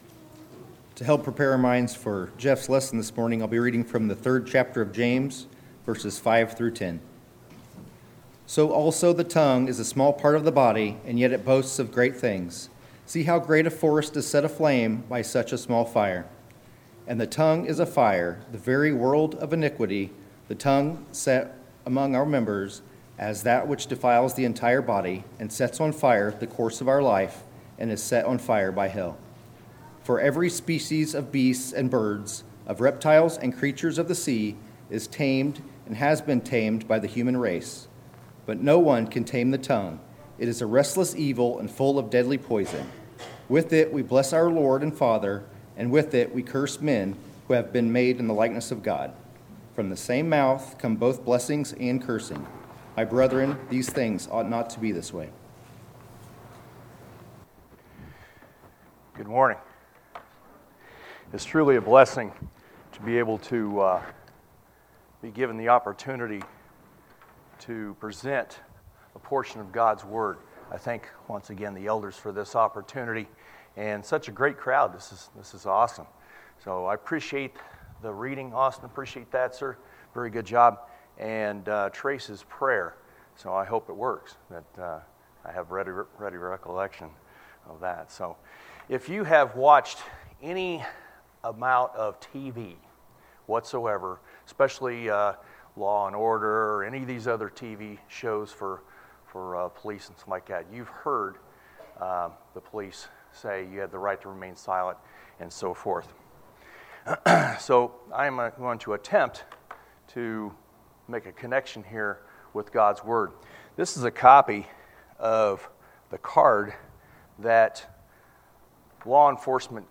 Sermons, July 28, 2019